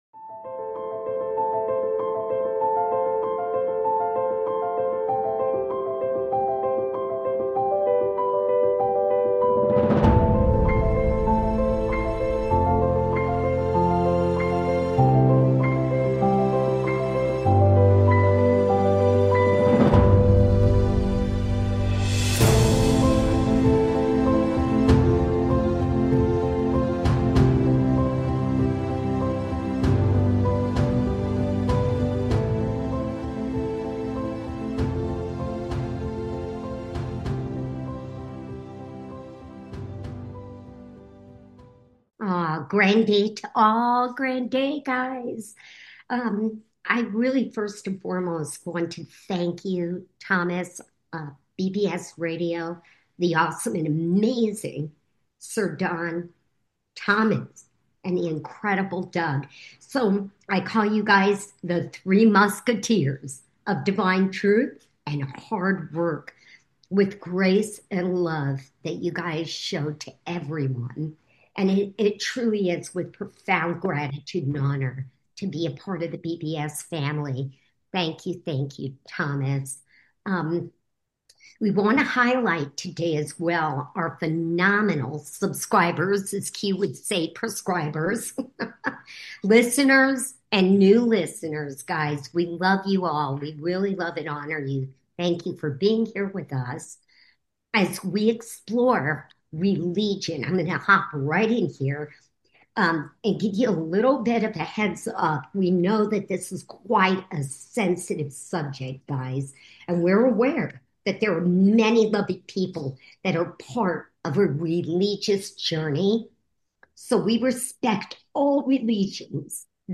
This show invites everyone to participate, featuring a host, along with 3 "Soul Hosts," and our divine masculine
Occasionally, we'll also take "call-ins" and conduct "one-on-one" interviews.